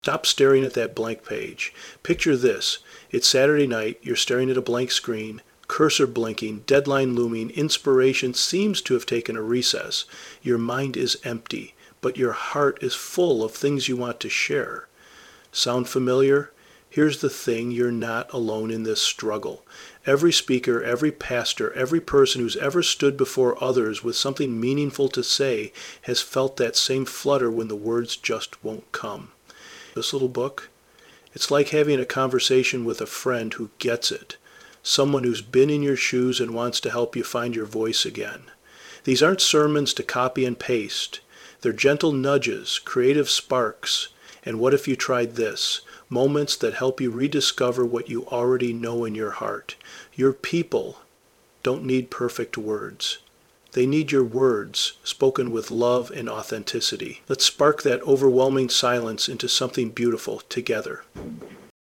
Audio Blurb
Sermon.Starters.I.Voiceover.mp3